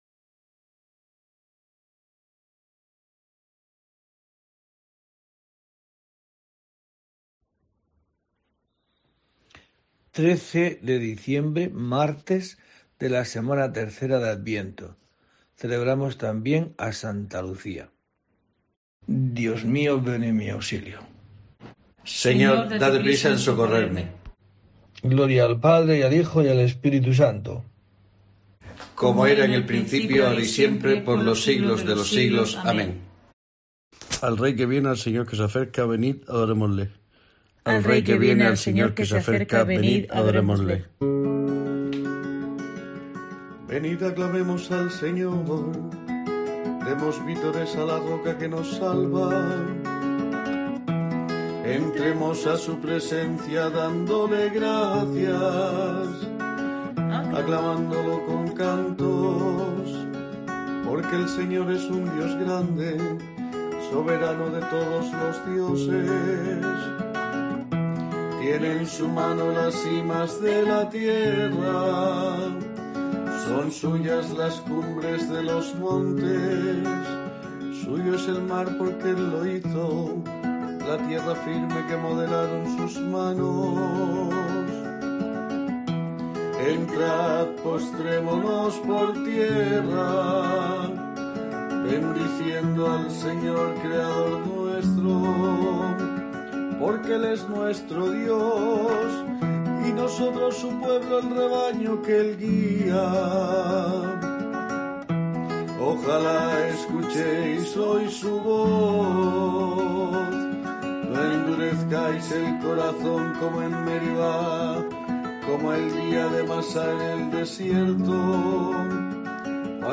En este día tiene lugar una nueva oración en el rezo de Laudes, marcado por la nueva normalidad.